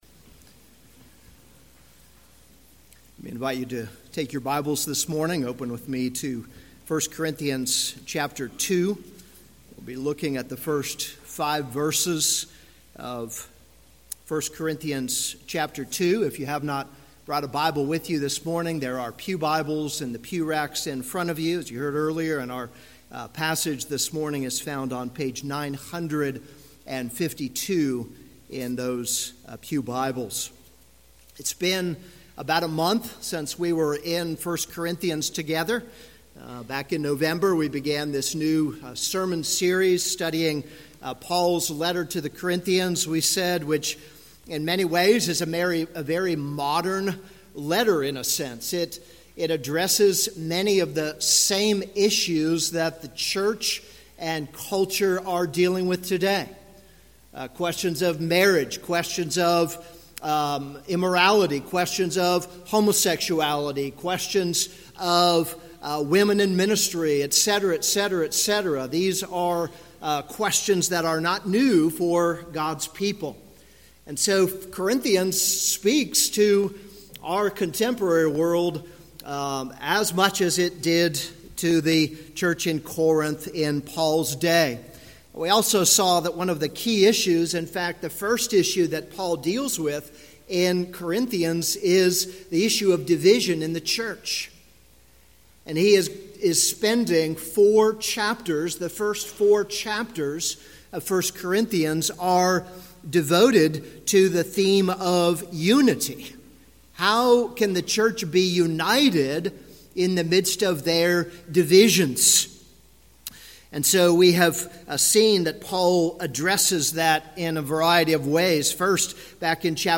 This is a sermon on 1 Corinthians 2:1-5.